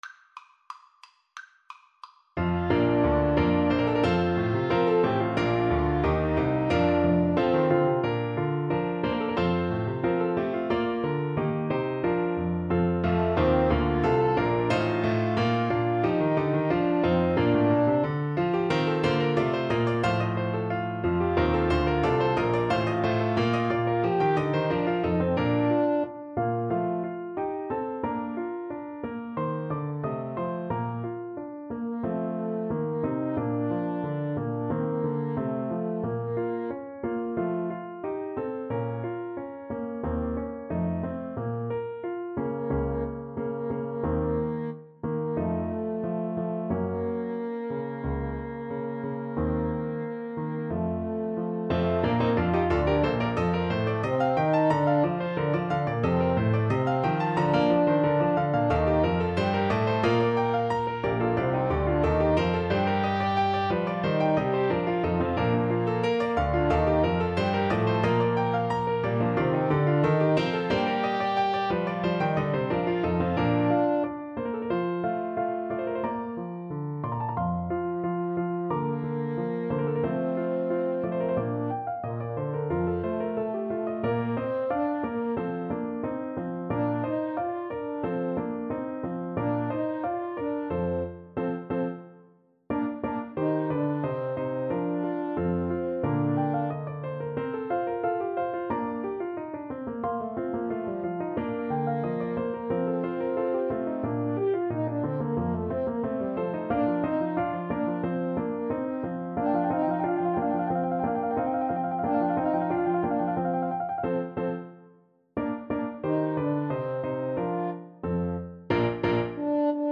Free Sheet music for Trumpet-French Horn Duet
Bb major (Sounding Pitch) (View more Bb major Music for Trumpet-French Horn Duet )
Allegro =180 (View more music marked Allegro)
4/4 (View more 4/4 Music)
Classical (View more Classical Trumpet-French Horn Duet Music)